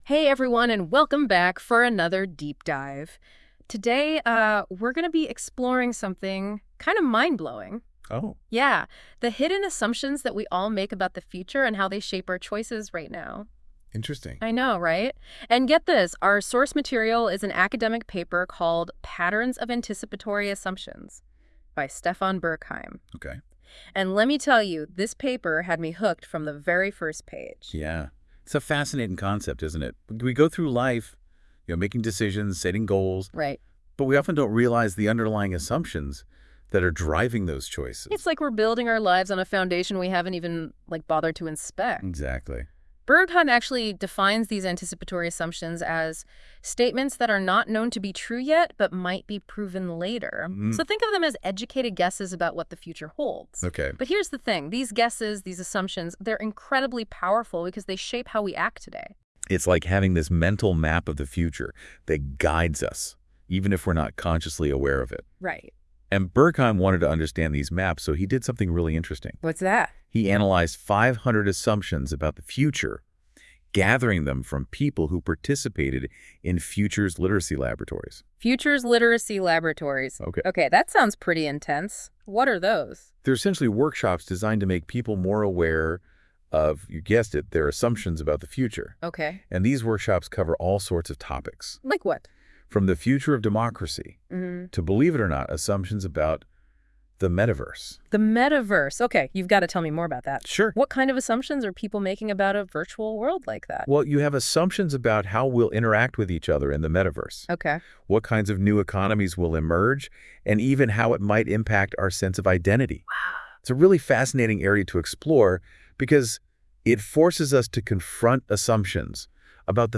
Dazu gibt es einen 13-minütigen KI-Podcast in englischer Sprache.
ai-podcast-anticipatory-assumptions.wav